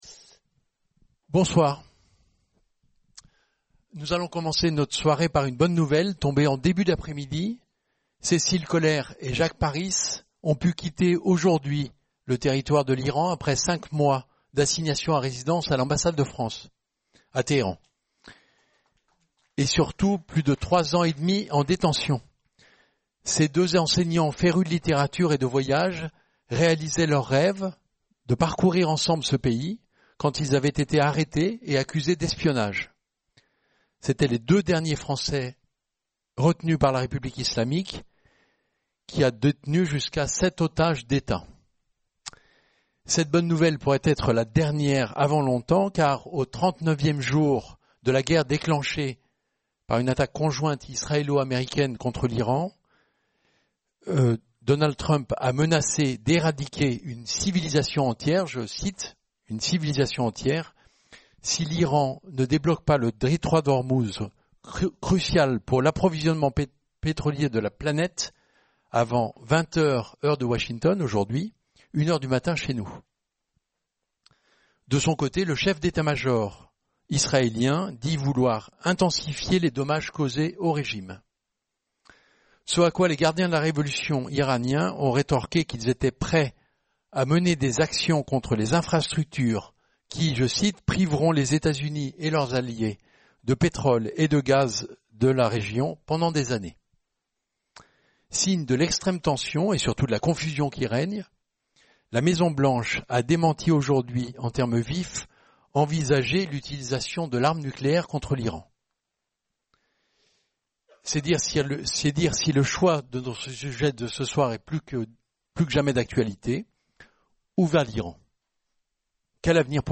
Débat